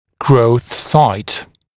[grəuθ saɪt][гроус сайт]место роста